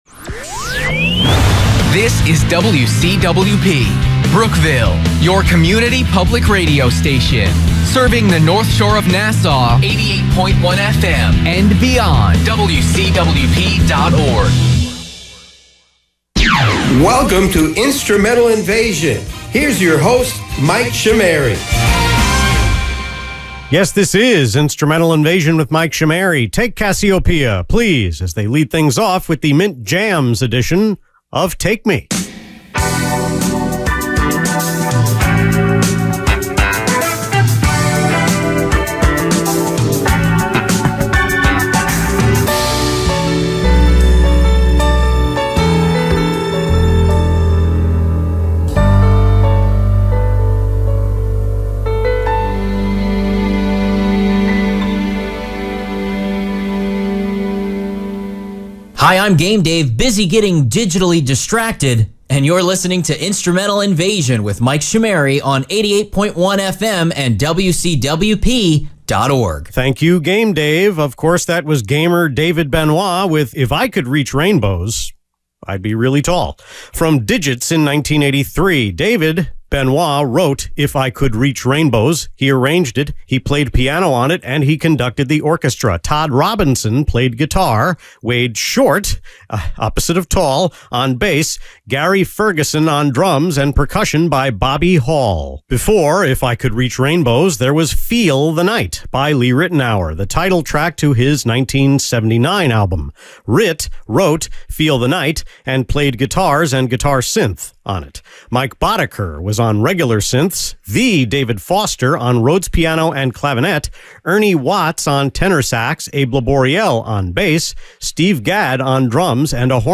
Pickups were also recorded to make up time left over. An additional pickup was done on June 25 (in my COVID-compromised voice) to remove a dated reference.
Airchecks